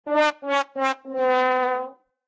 fail.ogg